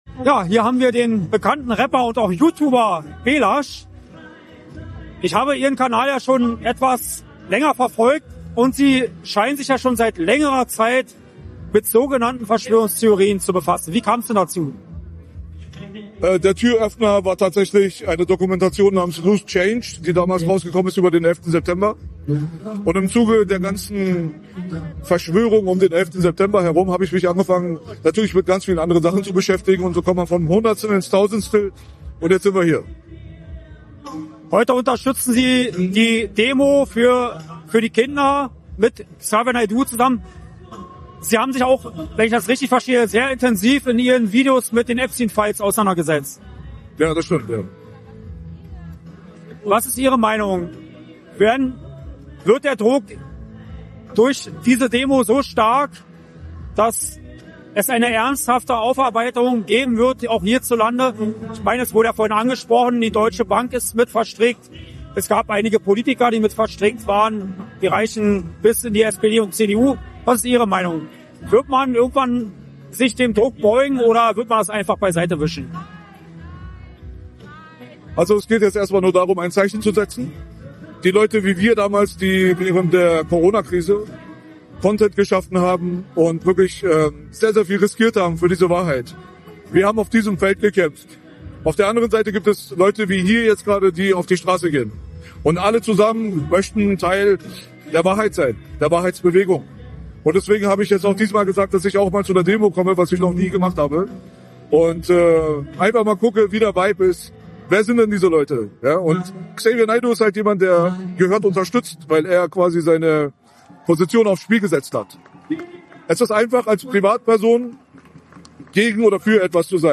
Auf der Demo gegen Kindesmissbrauch in Berlin konnte AUF1 mit dem